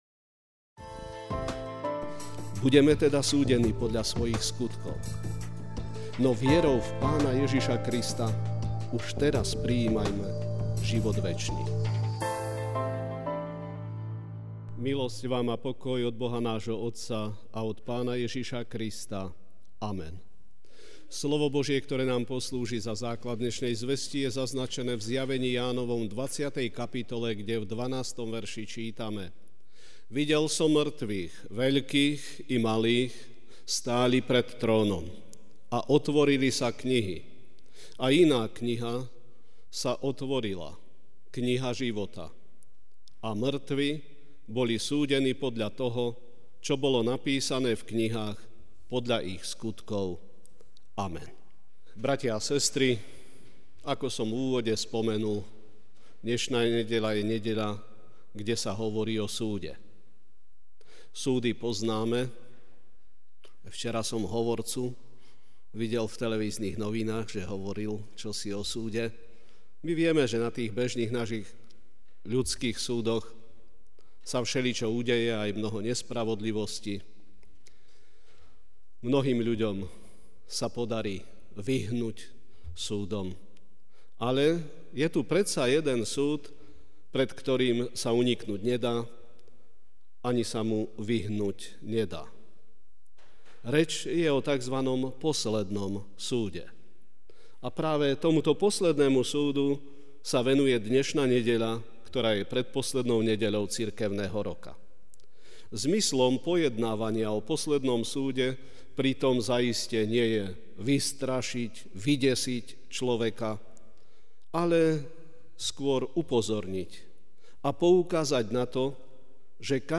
Večerná kázeň: Neminuteľný súd (Zj 20,12) 'Videl som mŕtvych, veľkých i malých: stáli pred trónom a knihy sa otvorili.